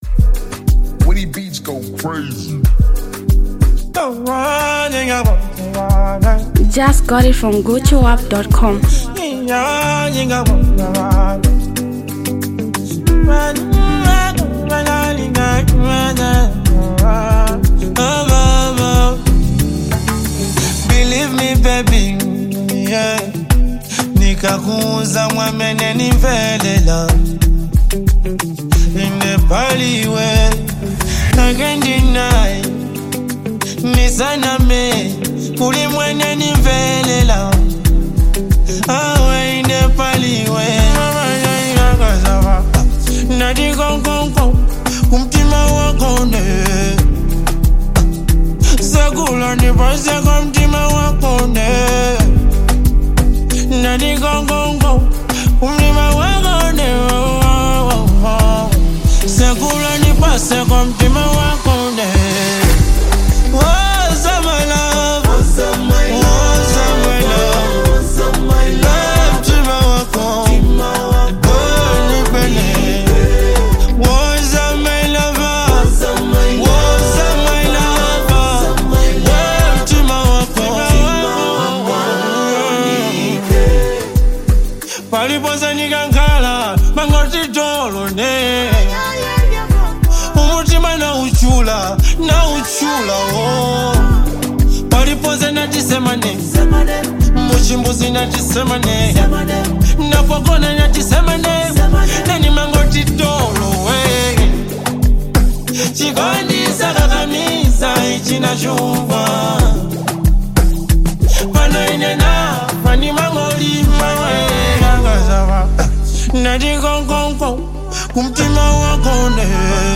is another lovable traditional song you need to listen to?